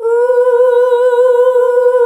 UUUUH   B.wav